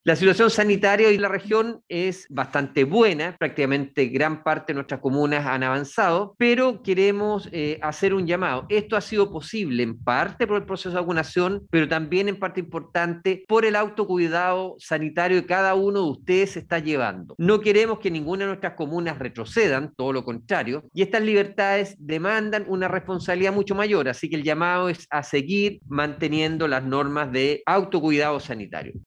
En tanto, respecto a la actual situación sanitaria en la región de Los Lagos, Alejandro Caroca, indicó: